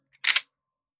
fps_project_1/30-30 Lever Action Rifle - Unloading 004.wav at bd991c21c9d364fa8f974ed0c9c47aeb70cc1b1f